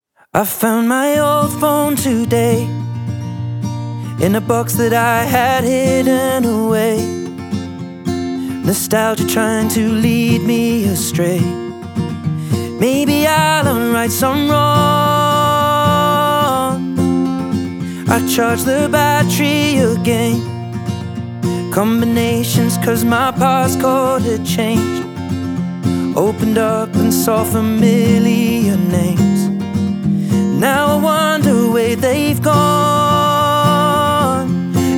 Pop Singer Songwriter
Жанр: Поп музыка